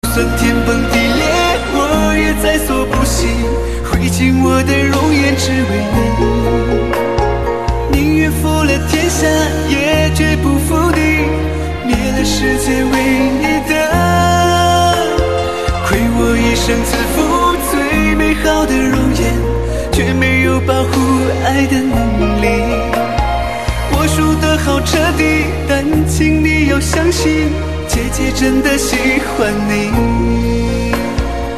M4R铃声, MP3铃声, 华语歌曲 65 首发日期：2018-05-15 05:37 星期二